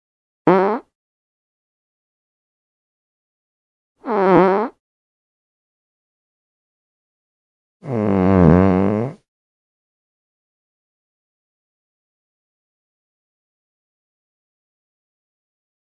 Звуки пердежа, пукания
Тихий звук пуканья человека